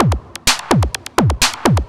DS 127-BPM B2.wav